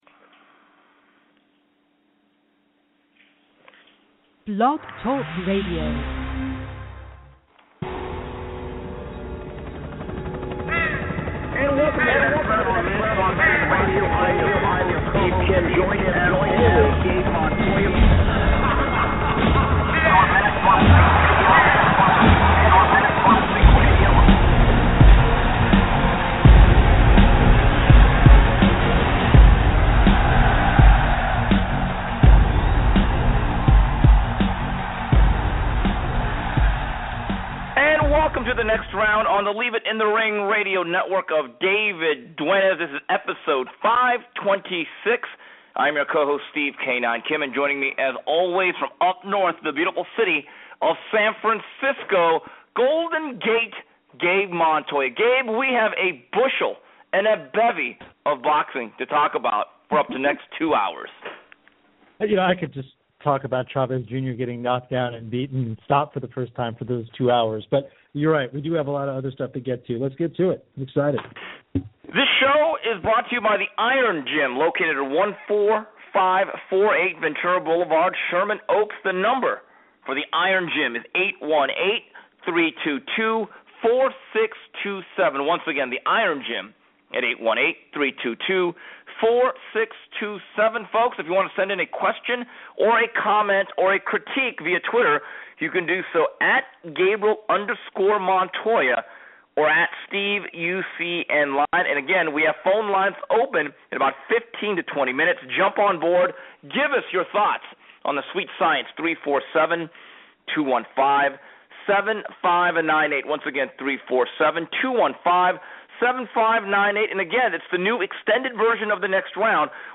Then the TNR crew will analyze the HBO heavyweight title fight showdown between Wladimir Klitschko and Bryant Jennings. And as always, news, notes and your calls.